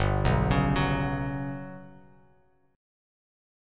GM#11 chord